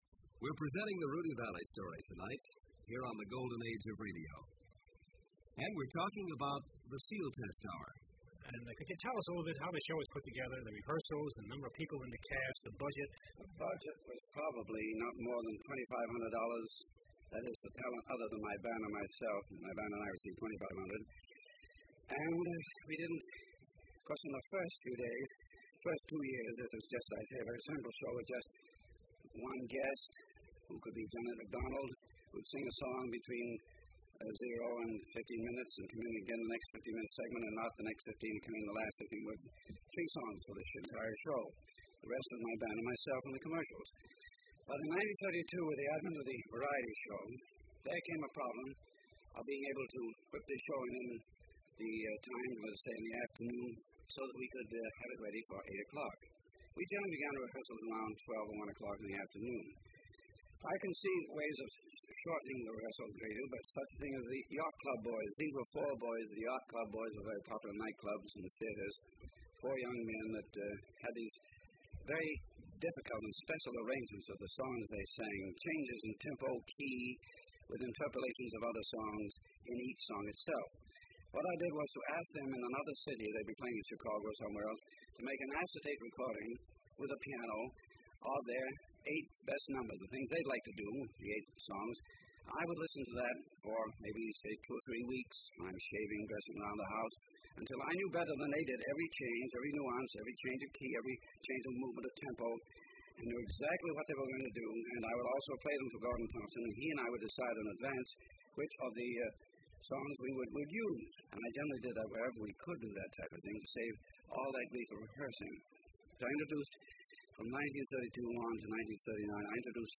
"The Golden Age of Radio" (As originally broadcast on WTIC, Hartford, CT)